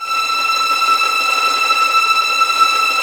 Index of /90_sSampleCDs/Roland - String Master Series/STR_Vlns Tremelo/STR_Vls Trem wh%